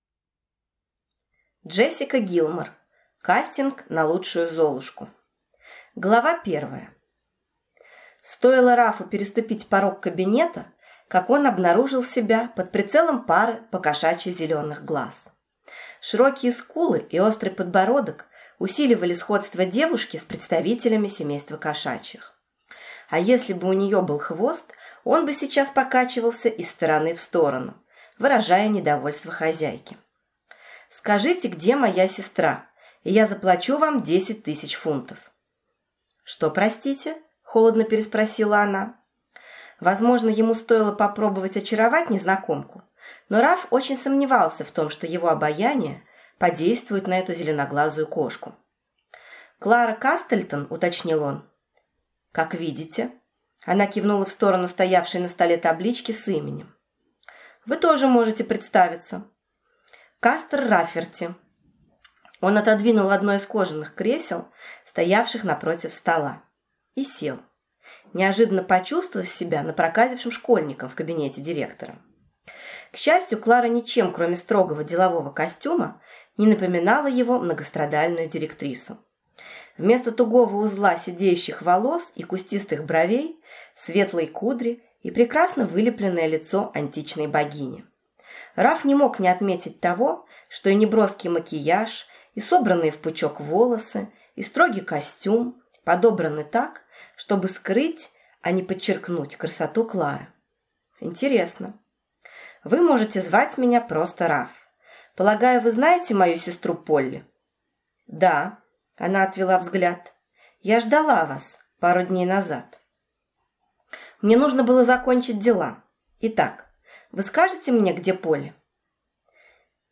Аудиокнига Кастинг на лучшую Золушку | Библиотека аудиокниг